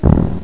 00182_Sound_fart2